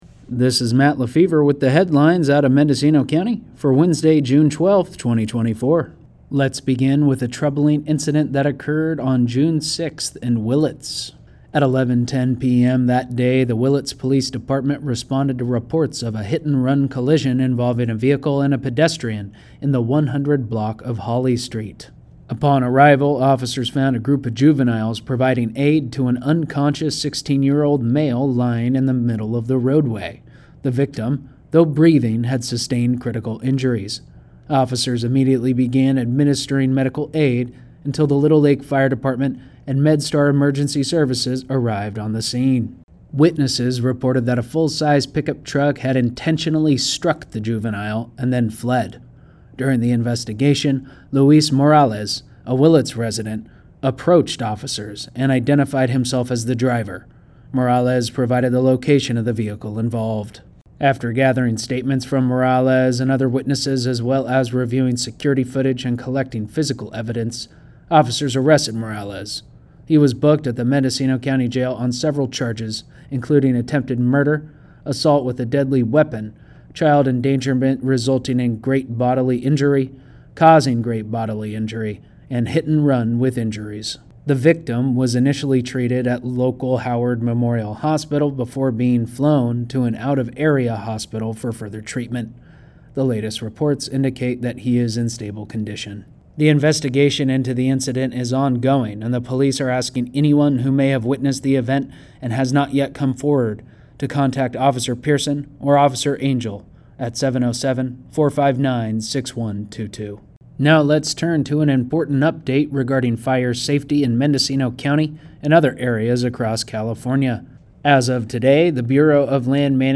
brings the latest headlines from Mendocino County